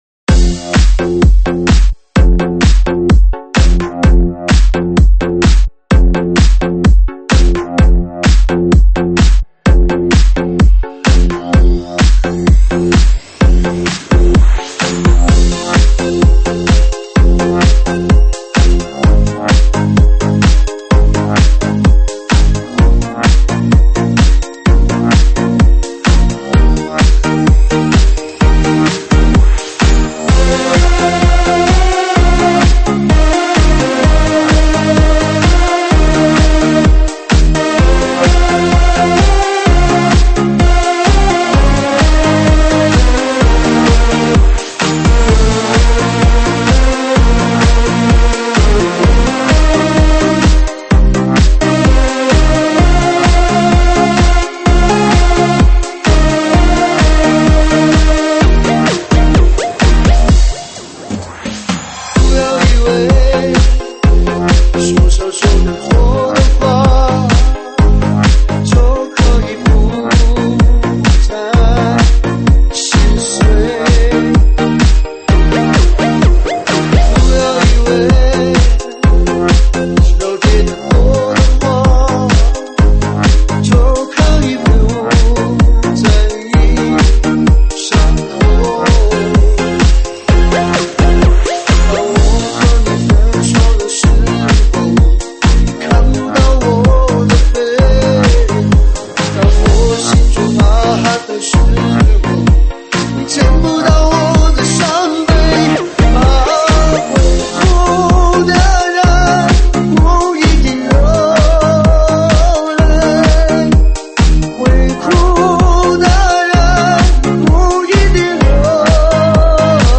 舞曲类别：ProgHouse